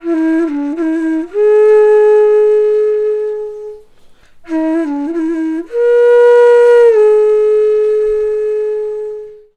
FLUTE-A03 -R.wav